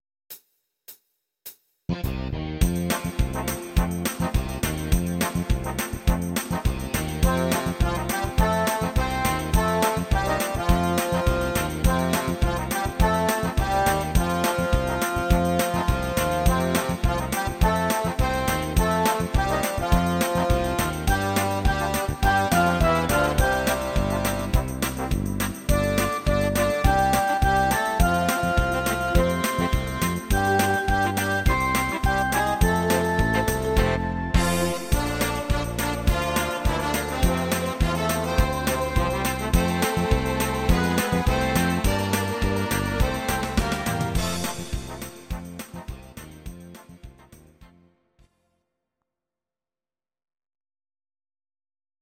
Audio Recordings based on Midi-files
Our Suggestions, Pop, German, 1970s